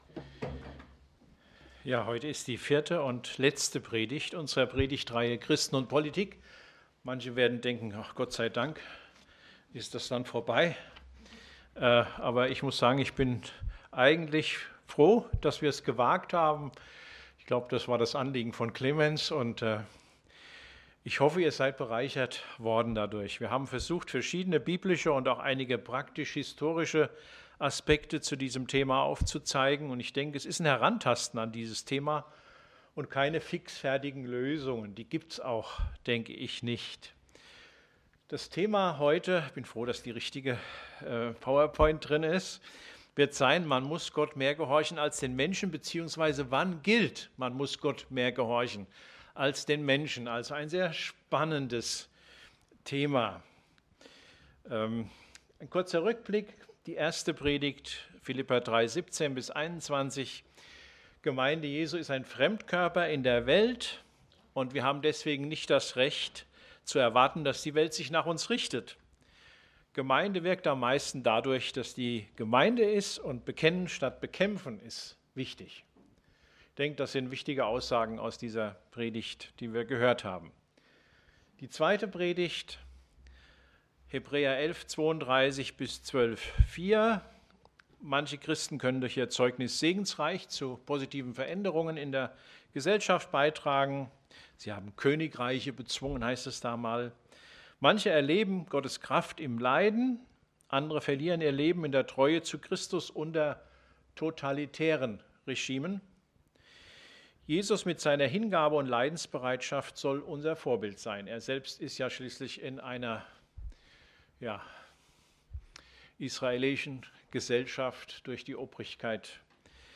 Aus der Predigtreihe: "Christen und Politik"